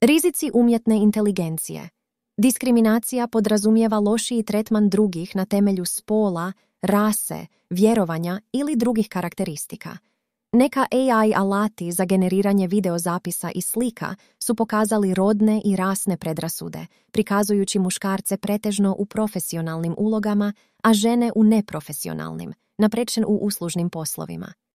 Text-to-voice
Primjena generativne umjetne inteligencije (voice over & sinhronizacija usana)